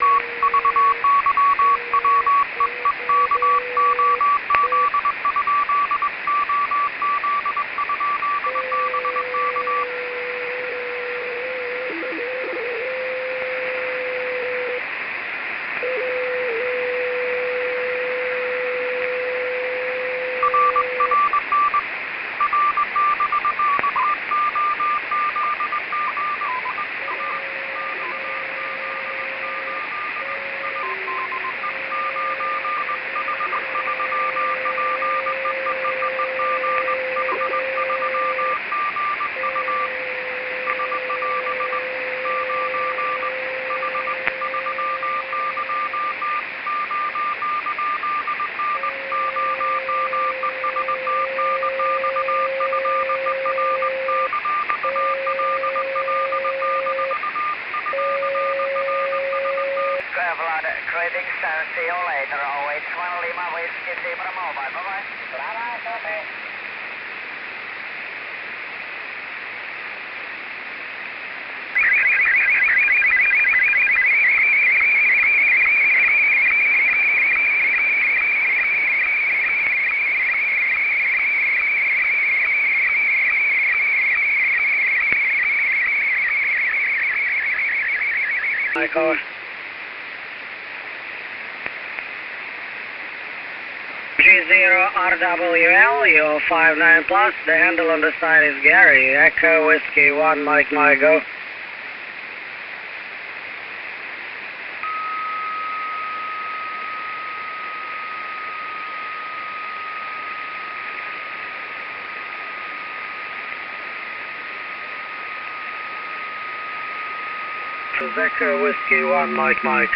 ricetrasmettitore SSB QRP in 20m
I file sono dei piccoli MP3 da uno o due minuti... c'è fonia, cw e rtty...
l'antenna è il dipolaccio ex_ricevitore, in pratica un pezzo di filo steso sul tetto,